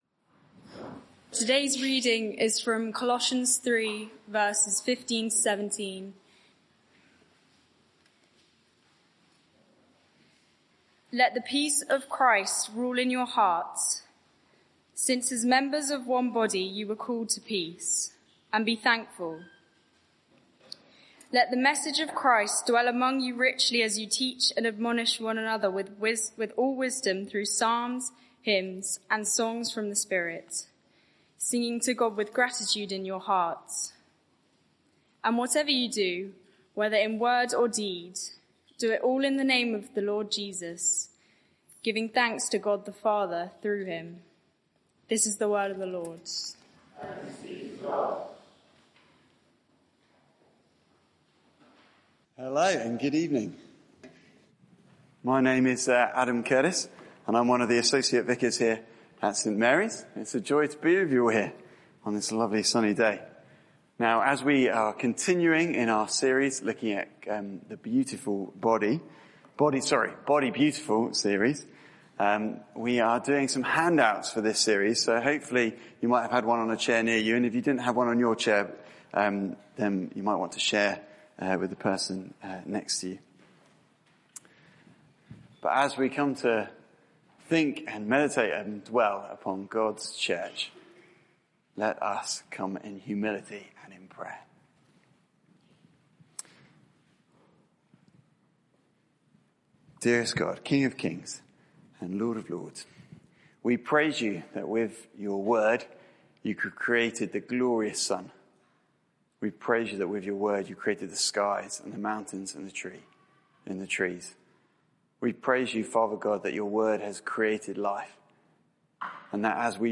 Media for 6:30pm Service on Sun 23rd Jun 2024 18:30 Speaker
Sermon